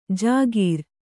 ♪ jāgīr